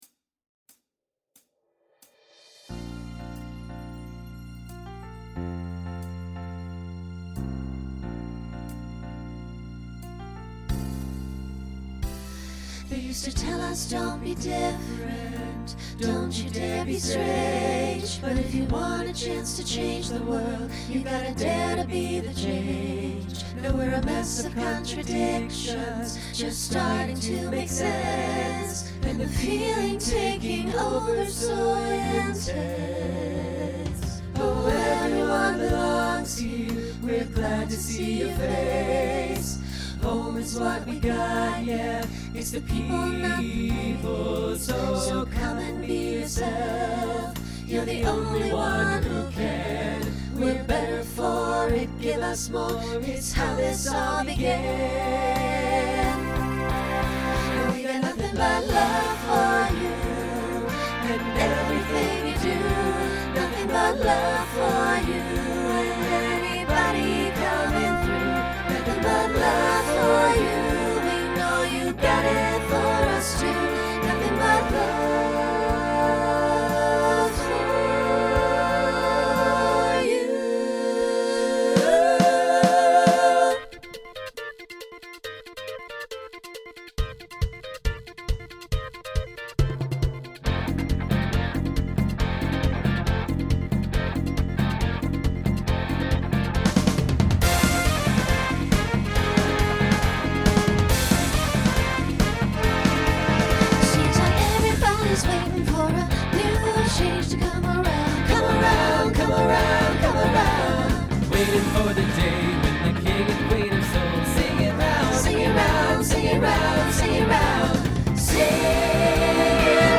2020s Genre Broadway/Film , Latin Instrumental combo
Voicing SATB